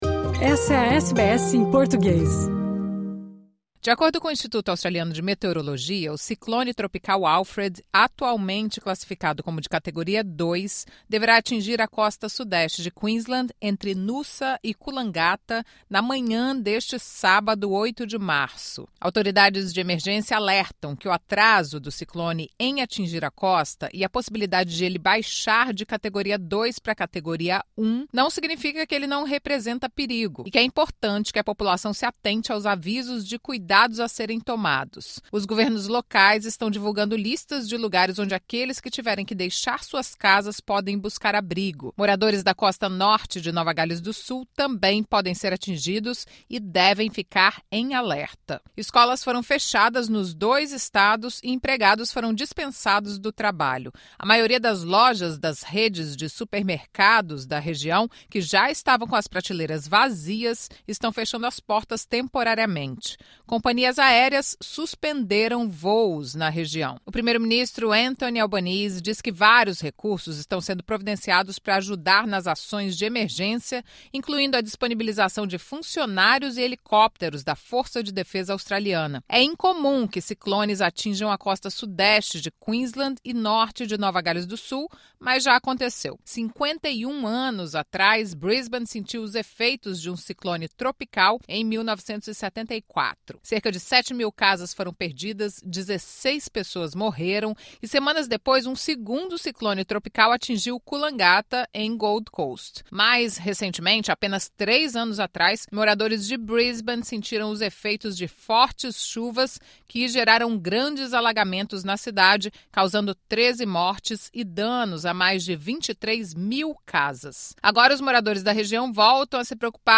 Ouvimos brasileiros e portugueses que moram na região e estão se preparando para a passagem do ciclone por lá.